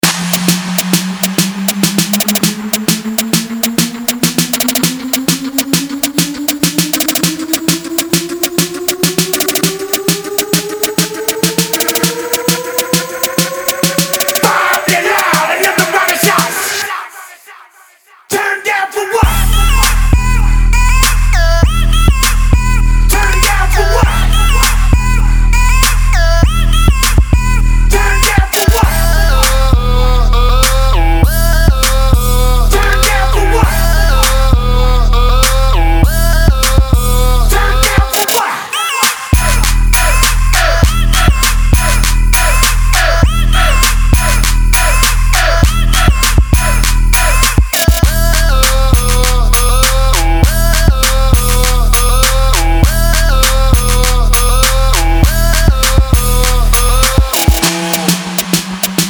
زنگ موبایل شاد